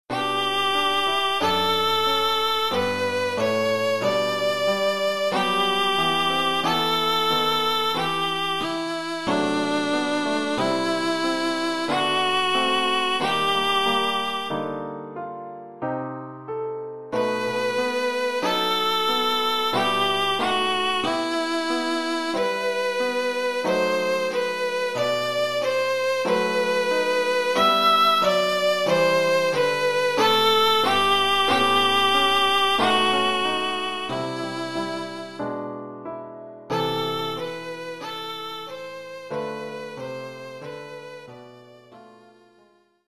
1 titre, violon et piano : conducteur et partie de violon
Oeuvre pour violon et piano.